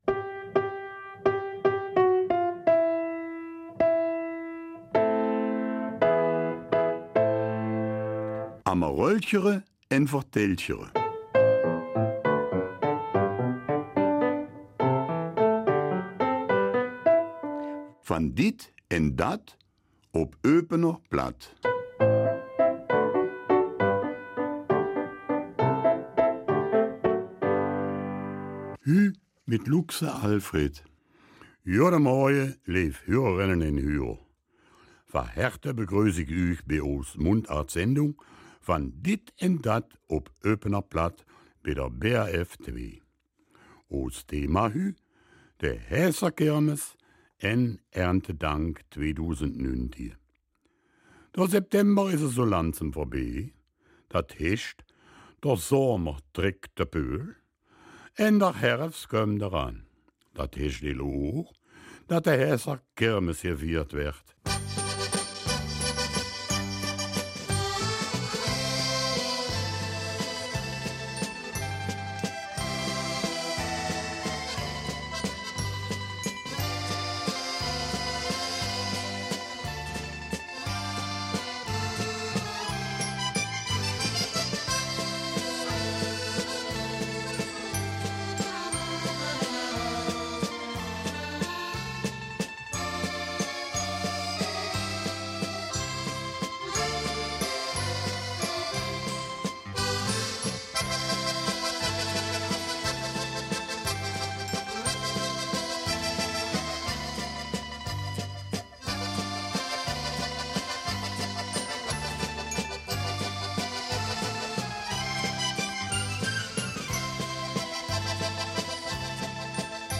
Eupener Mundart - 22. September